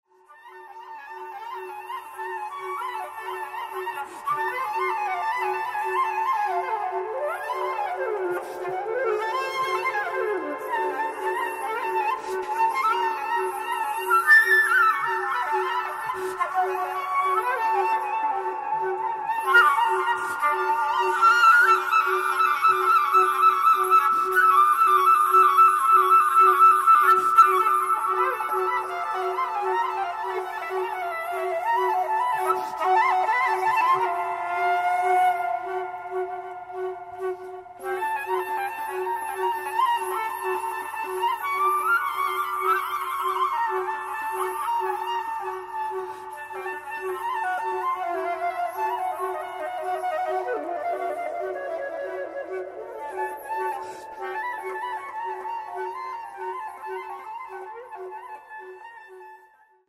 flute
live eletronics
E' un progetto di stampo minimalista